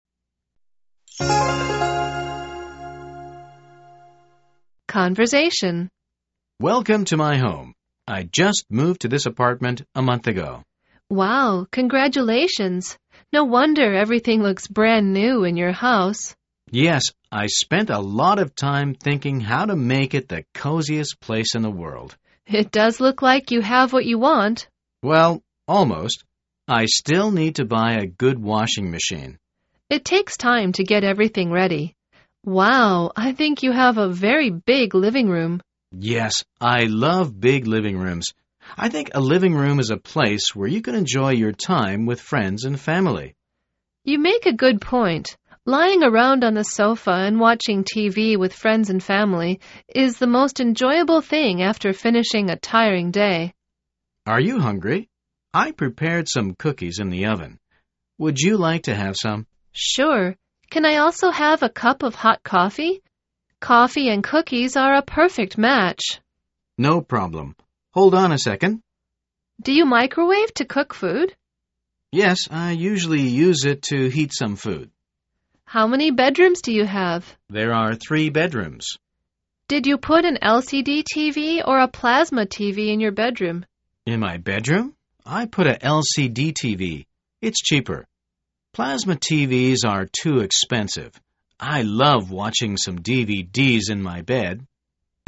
口语会话